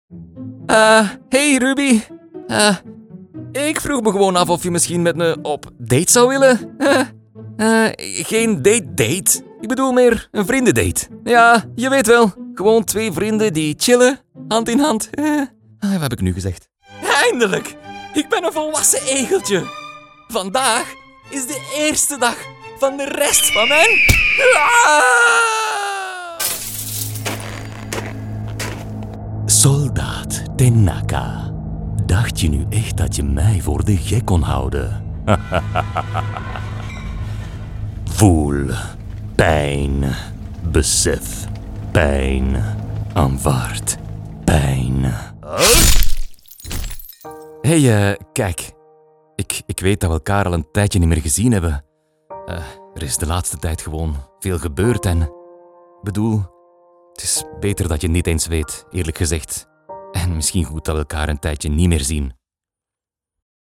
Cartoon & Character Voice Overs | The Voice Realm
0703FLEMISH_Character.mp3